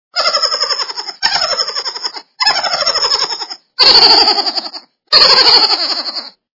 Смех - мышонка Звук Звуки Сміх - мишеняти
» Звуки » Смешные » Смех - мышонка
При прослушивании Смех - мышонка качество понижено и присутствуют гудки.